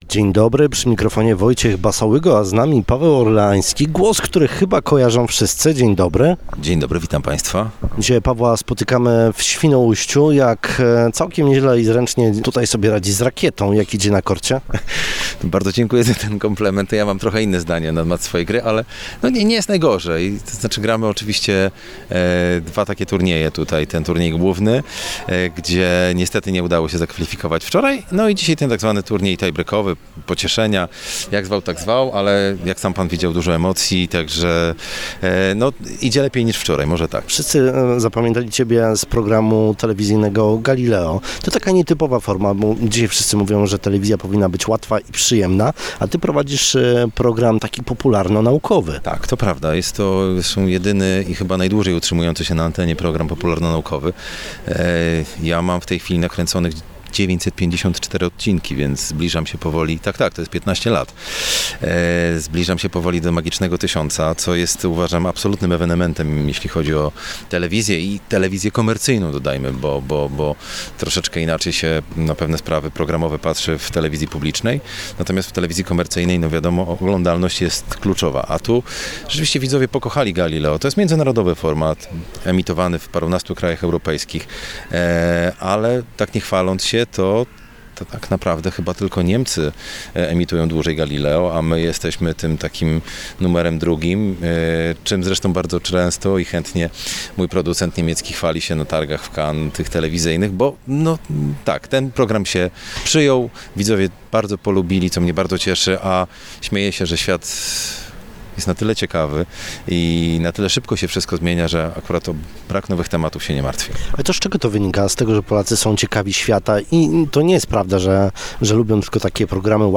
Posłuchajcie dzisiejszej Rozmowy Dnia – gościem był Paweł Orleański, aktor, którego spotkaliśmy w Świnoujściu na XVII Morskim Turnieju Tenisowym Gwiazd.